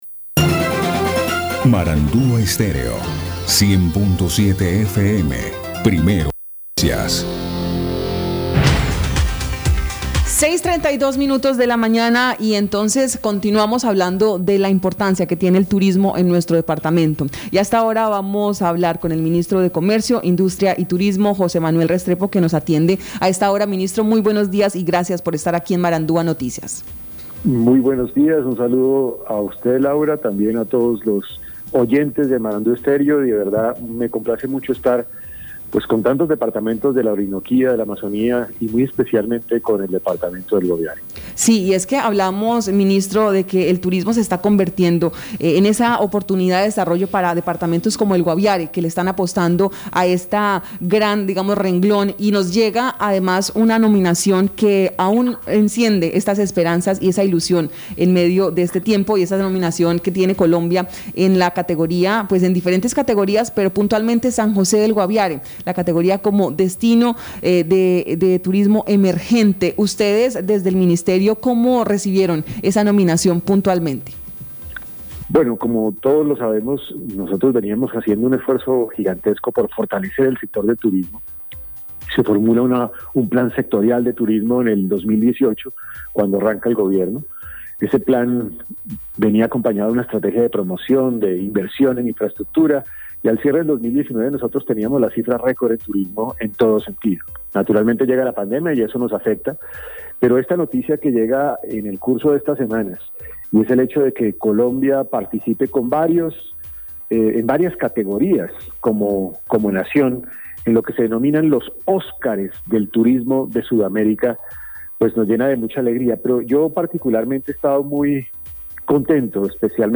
Escuche a José Manuel Restrepo, ministro de Industria, Comercio y Turismo.